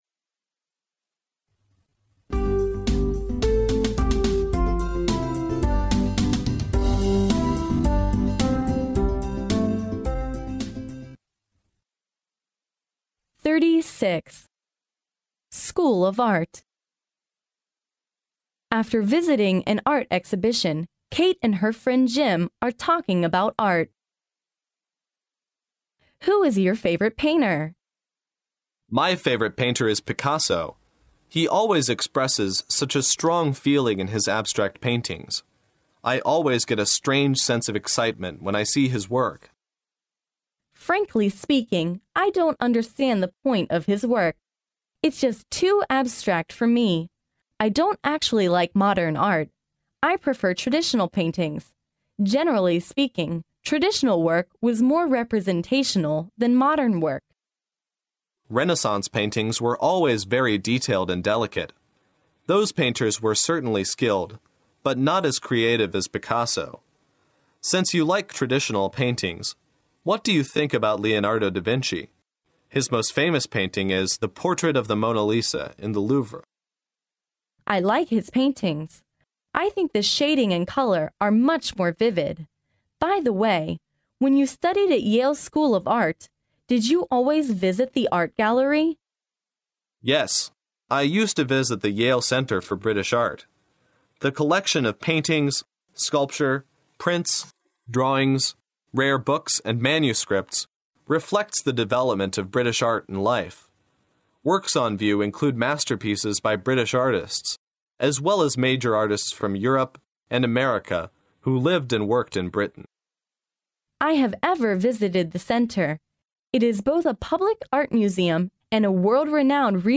耶鲁大学校园英语情景对话36：艺术学院（mp3+中英）